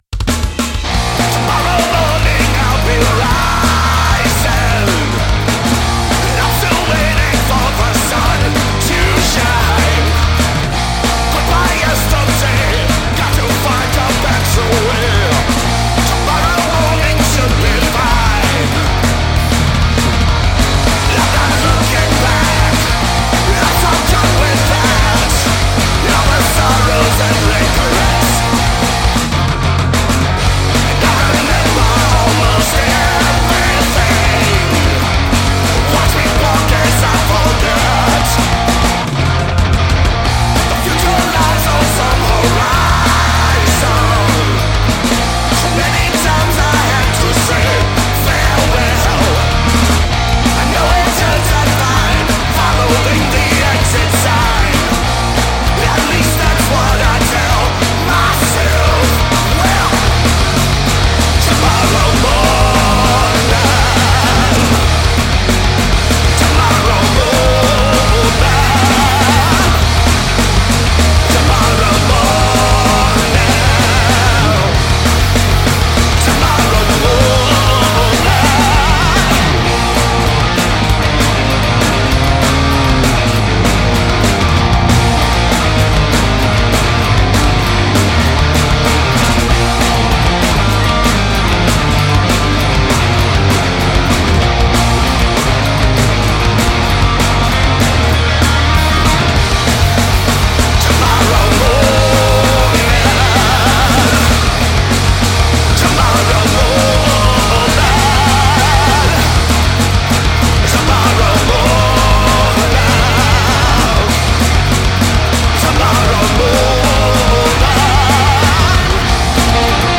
punk rock side project
brash and fast songs